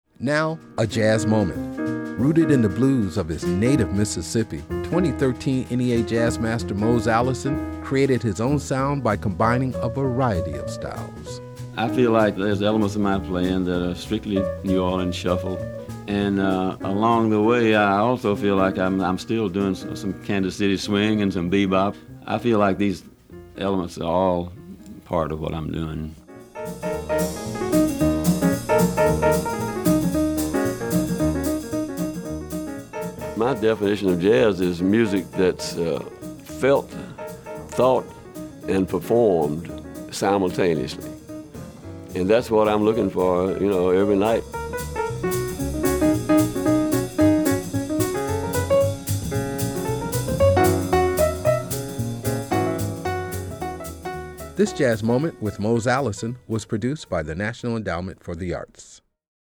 Music Credit: Excerpt of “Train” and “Saturday” written and performed by Mose Allison from the album, Back Country Suite, used courtesy of Concord Records, and used by permission of Audre Mae Music (BMI).
Audio Credit: Excerpt of Allison from an interview with Ben Sidran featured on the cd, Talking Jazz Volume 19, used courtesy of Ben Sidran.